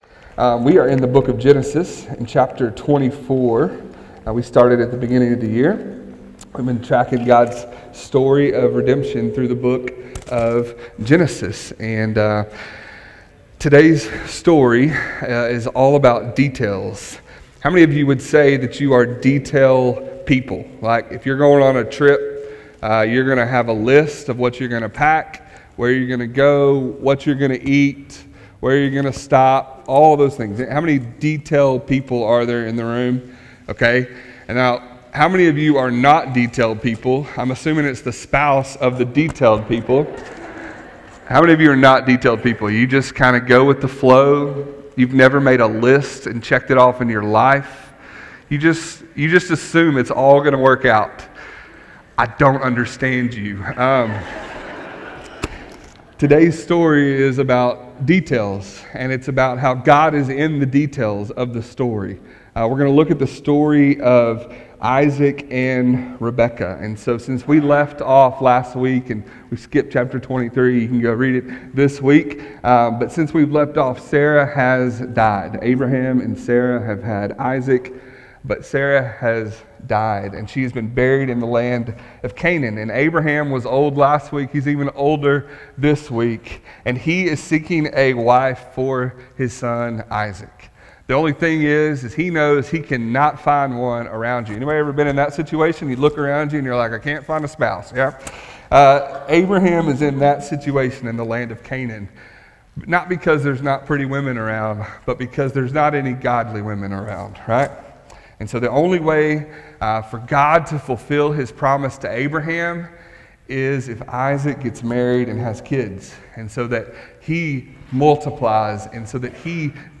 Weekly Sermons from Huntington First Baptist Church.